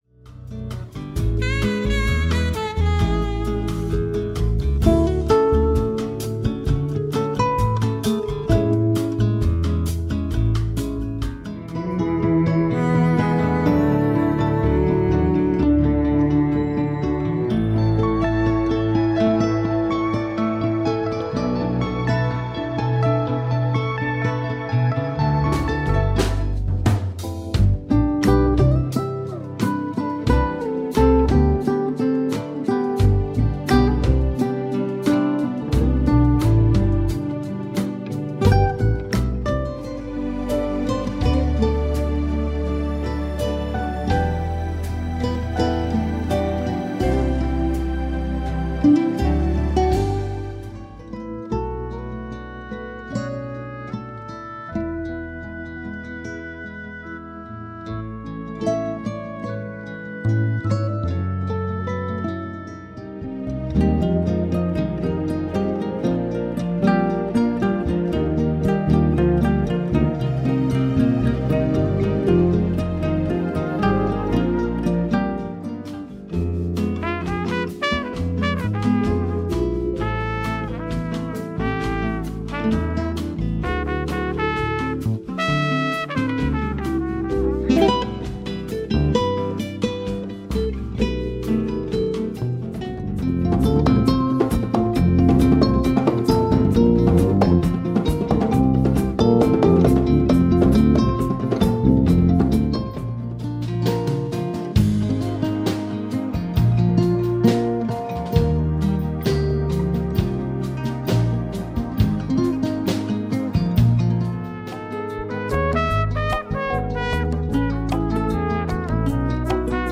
Instrumental Album of the Year (2013-GMA Canada)
Canadian instrumental guitarist